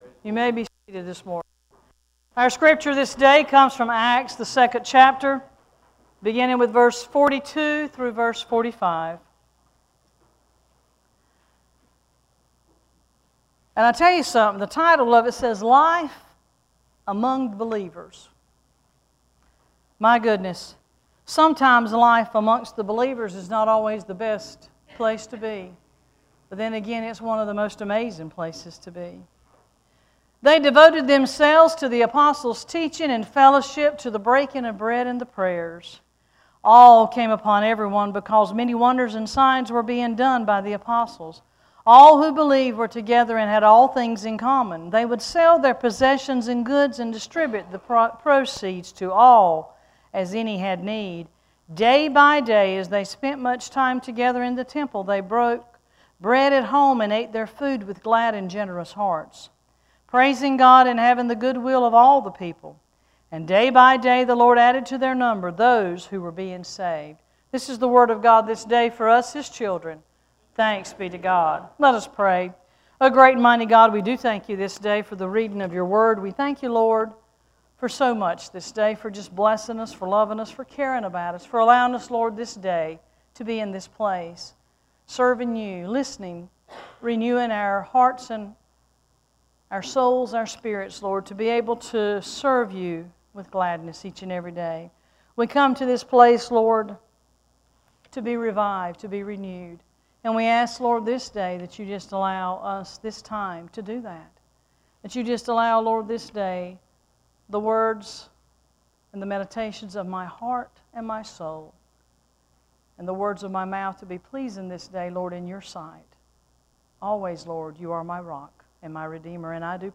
Worship Service 5-11-14: Experience the Living Christ
This weeks scripture and sermon: